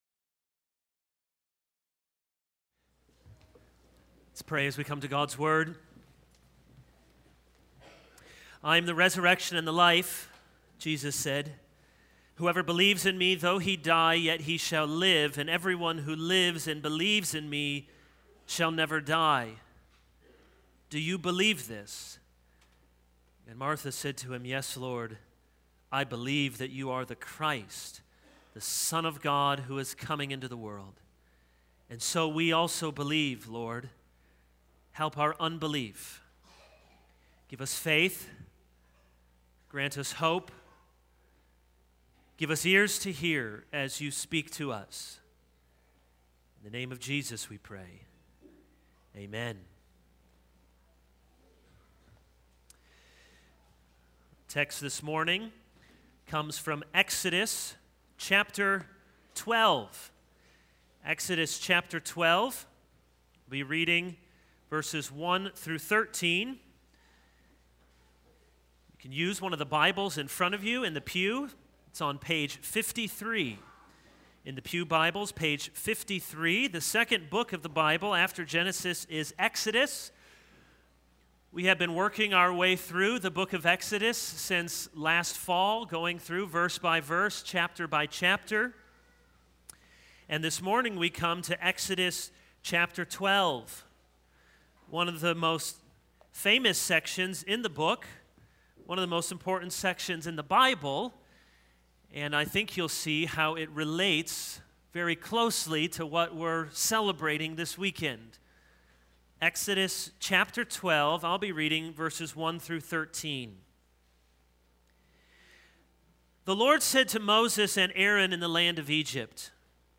This is a sermon on Exodus 12:1-13.